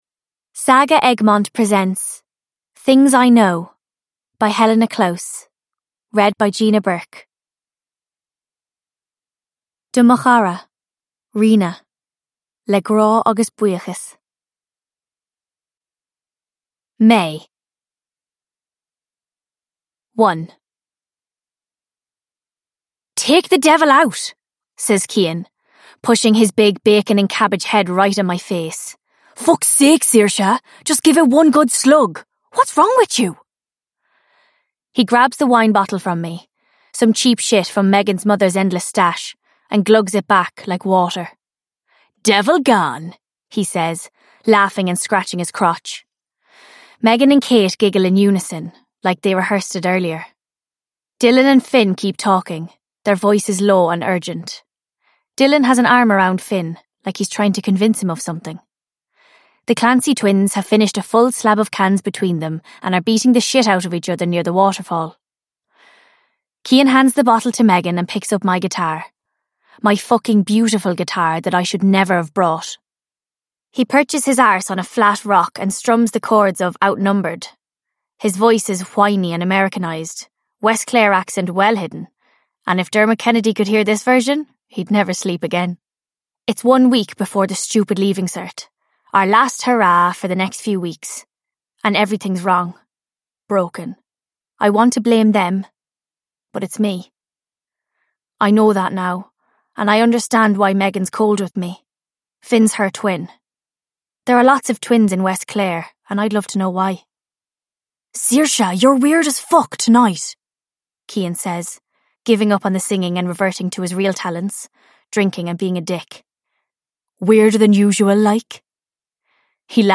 Things I Know: A deeply moving YA novel about mental illness and recovery – Ljudbok